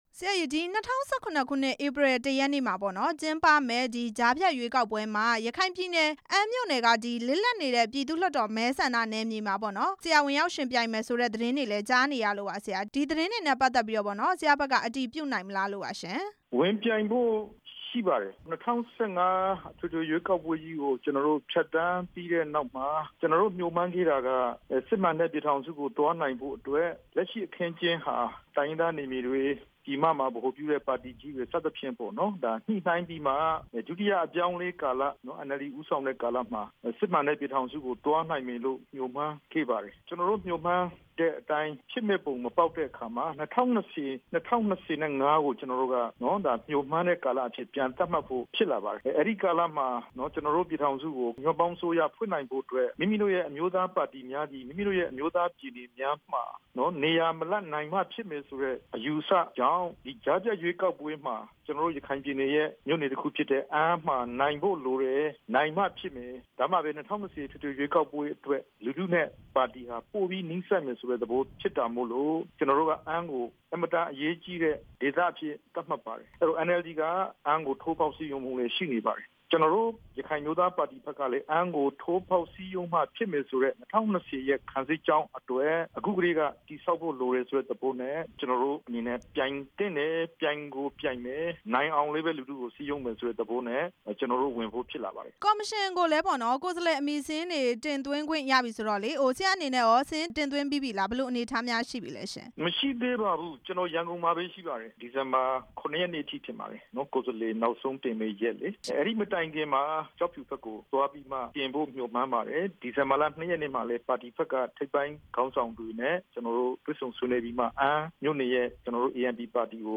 ကြားဖြတ်ရွေးကောက်ပွဲ ဝင်ပြိုင်မယ့် ဒေါက်တာအေးမောင် နဲ့ မေးမြန်းချက်